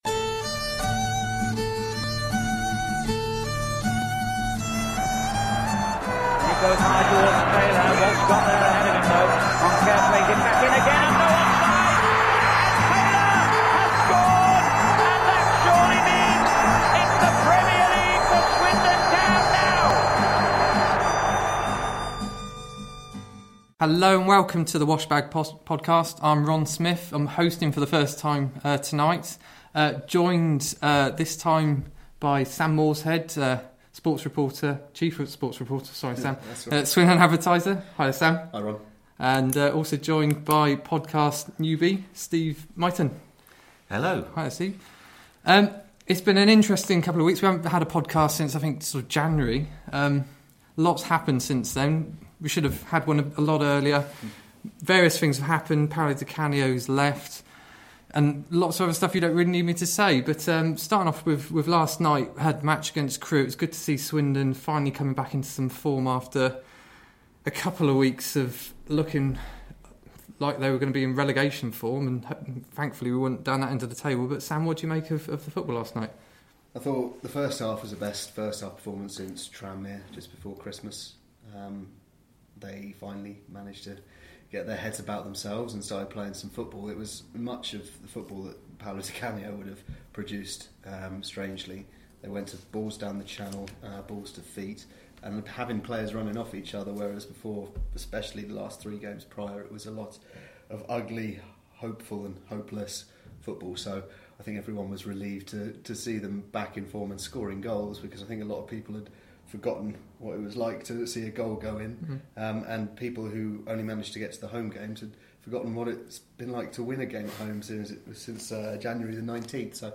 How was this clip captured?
I’m still experimenting with the sound levels so please bear with me while I sort these, get everyone sitting in the correct position, not leaning too far towards the mic and learning how to use this new microphone to it’s full potential…